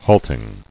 [halt·ing]